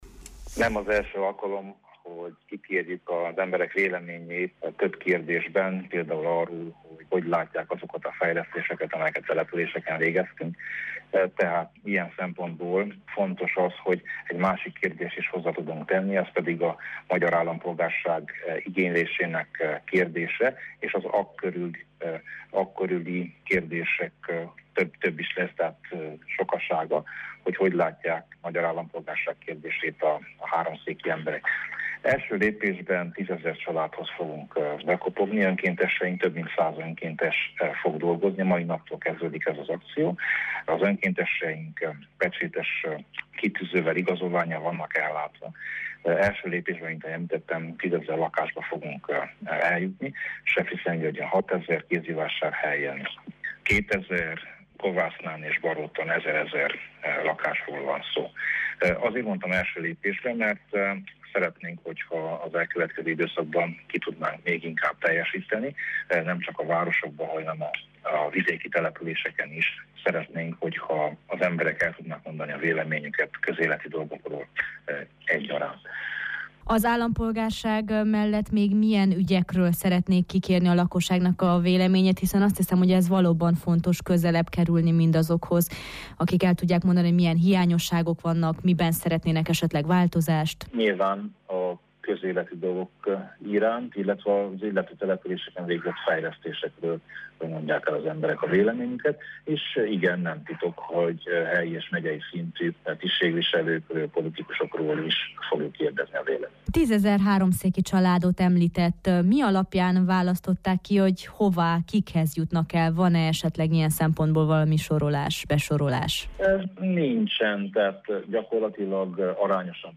Városi konzultáció keretében, tízezer háromszéki család véleményét kéri ki az RMDSZ, közéleti kérdésekben. A részleteket Tamás Sándortól, a Szövetség háromszéki területi szervezetének elnökétől tudjuk meg